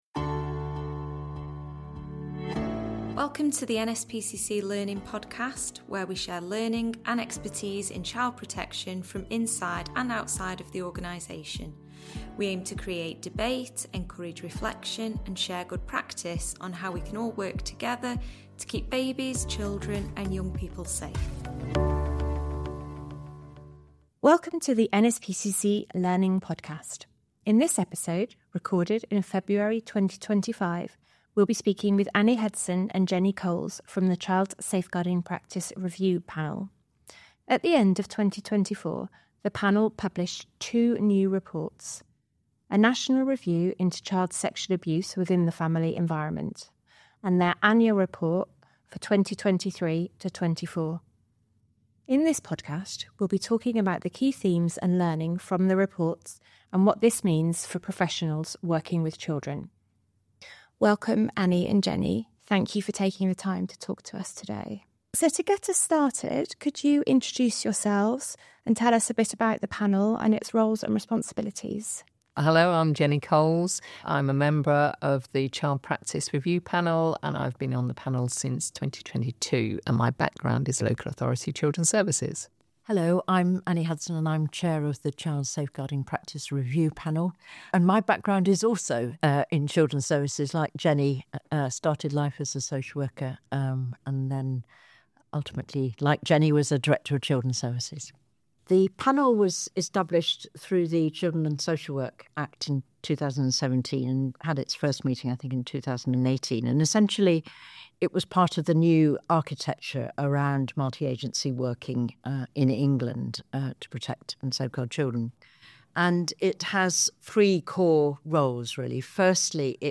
Part one of a discussion with members of the Child Safeguarding Practice Review Panel.